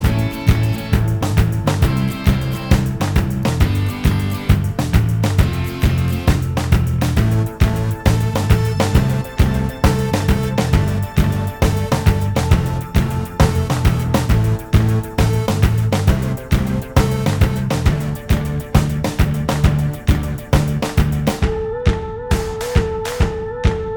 Minus Main Guitar Indie / Alternative 4:12 Buy £1.50